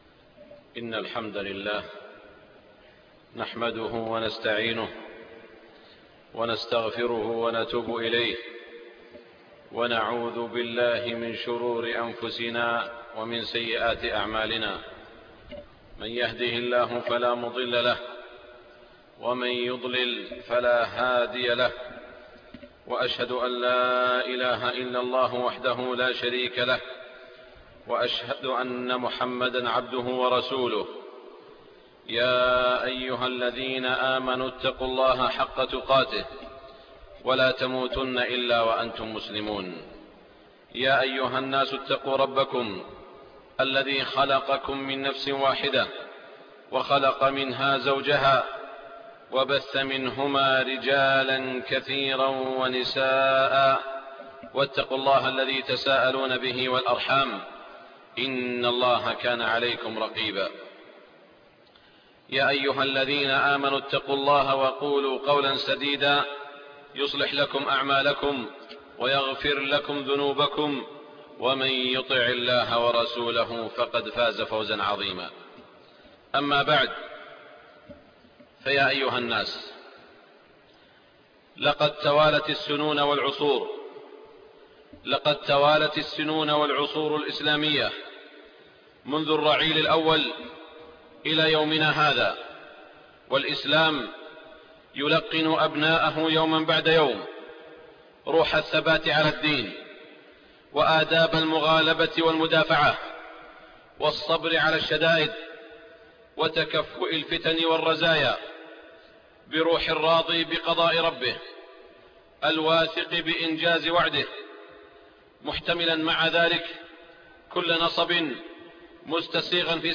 خطبة الجمعة 4 ربيع الاول 1428هـ > خطب الحرم المكي عام 1428 🕋 > خطب الحرم المكي 🕋 > المزيد - تلاوات الحرمين